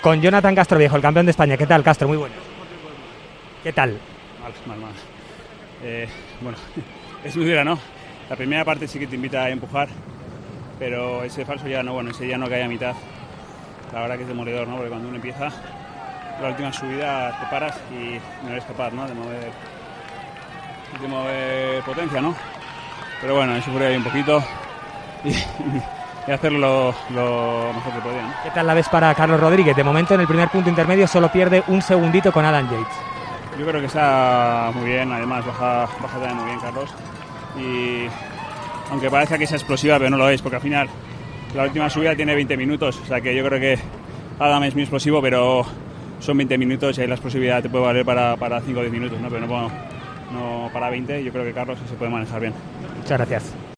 tras acabar la crono del Tour